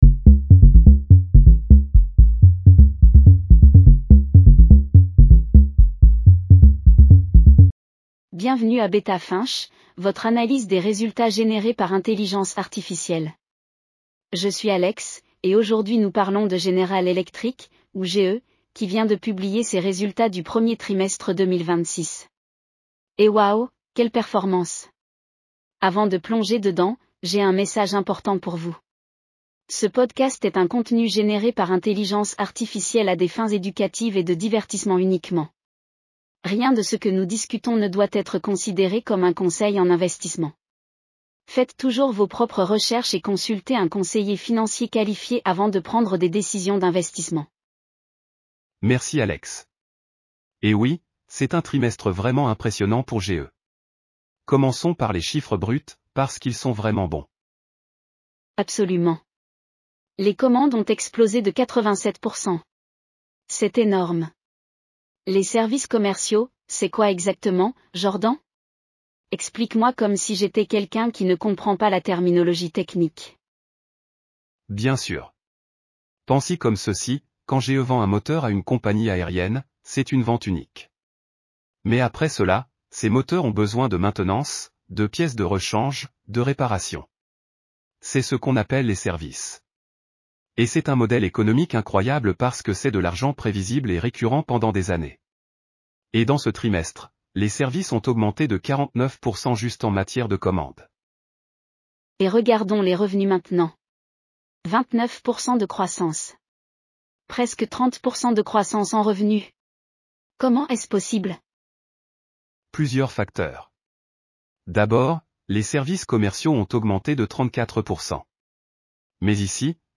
GE Aerospace Q1 2026 earnings call breakdown. Full transcript & podcast. 11 min. 5 languages.